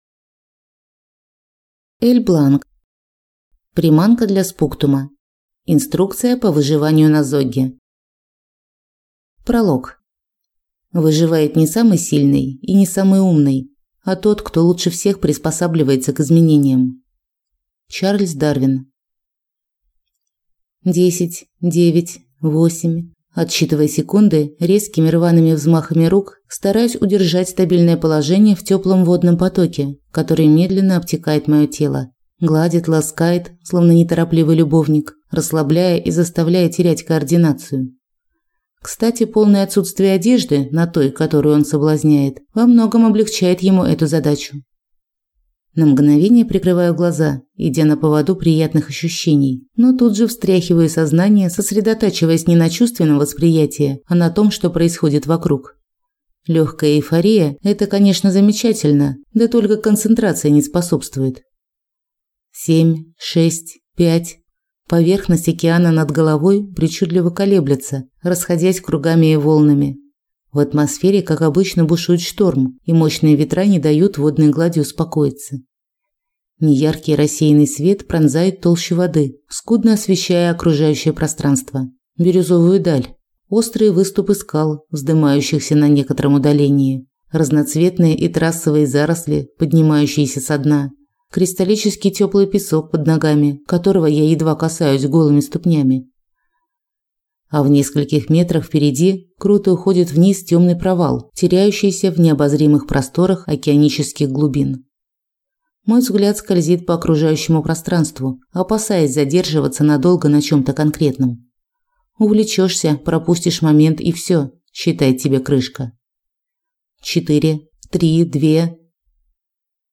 Аудиокнига Приманка для спуктума. Инструкция по выживанию на Зогге | Библиотека аудиокниг